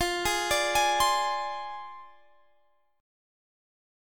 Fm6 Chord
Listen to Fm6 strummed